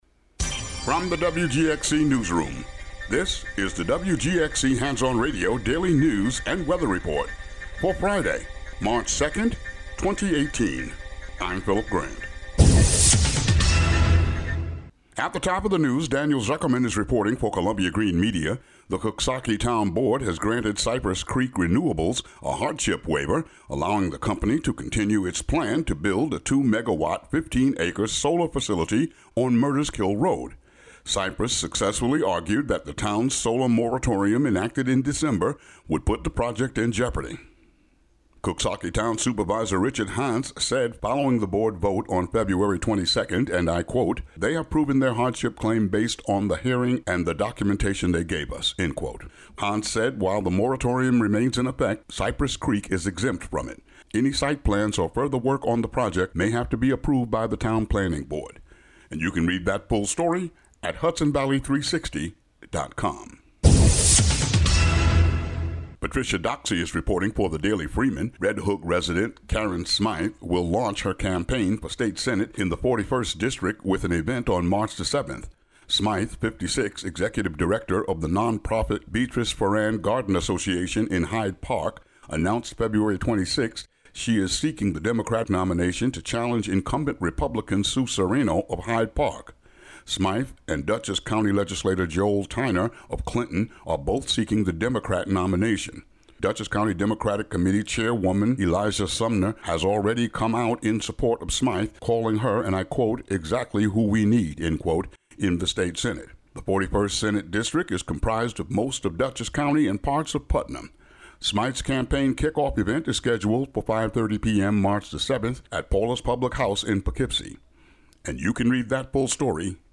Latest news from the WGXC Newsroom.